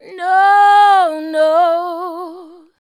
NO NO.wav